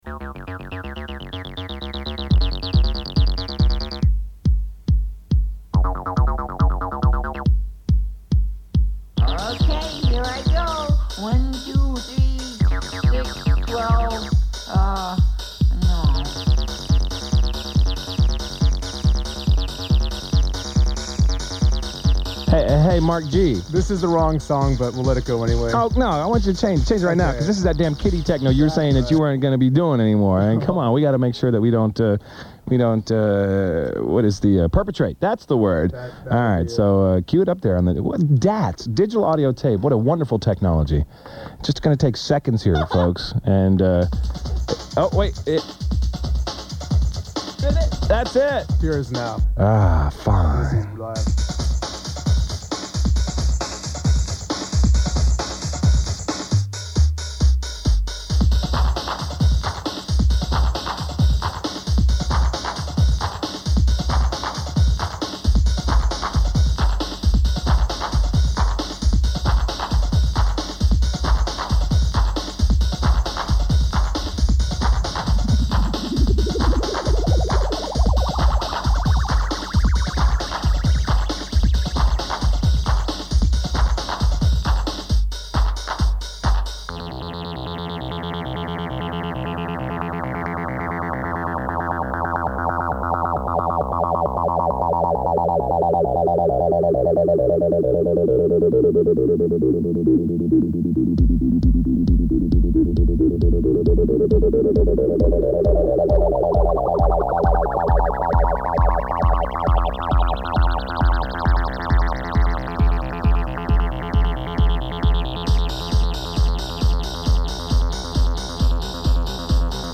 During an on-air interview on the dance mix show Edge Club 94
plays a song with a burbling TB303 synth and a high-pitched Elmer Fudd-like voice saying "OK
plays an amusingly frantic 303 workout with hyperactive drum loops (this was during the 'ardkore era).
The track ends, followed by several seconds of dead air, then some rather horrible digitized fed-back guitar chops.